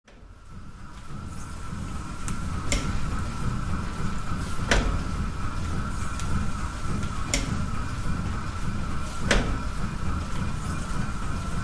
Звуки пара
Шум старинного парового двигателя